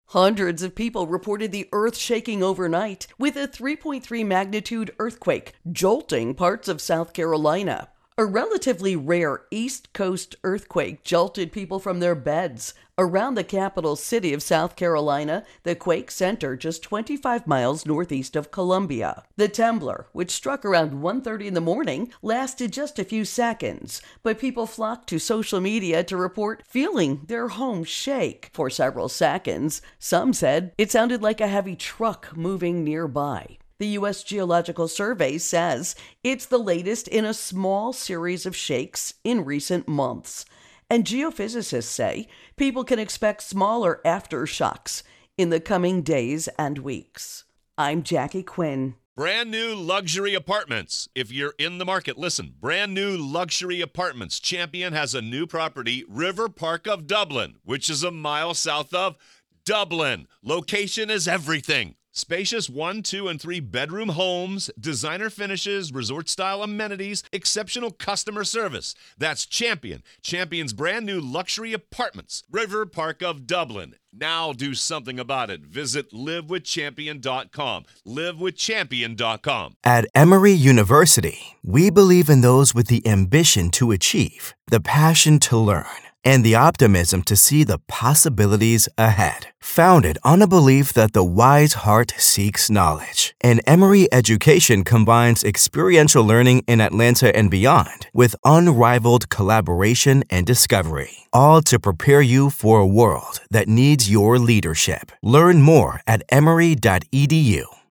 South Carolina Earthquake Intro and Voicer